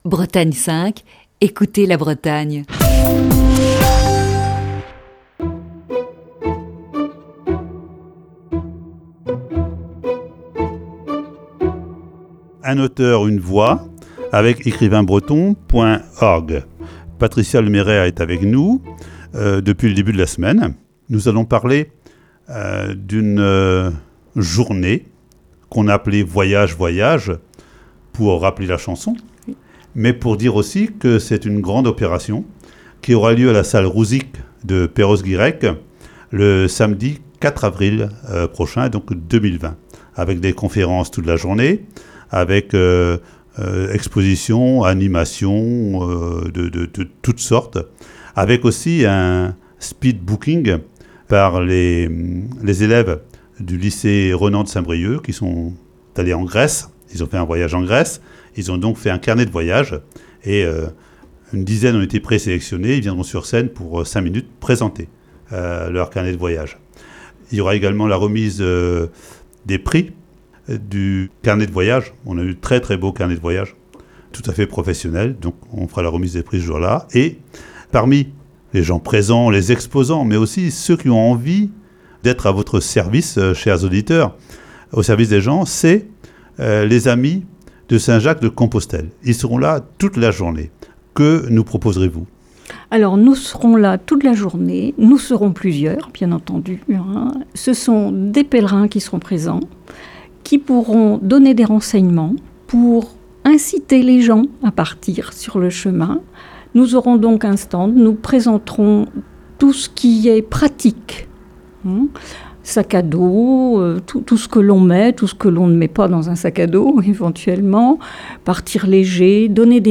Chronique du 10 avril 2020.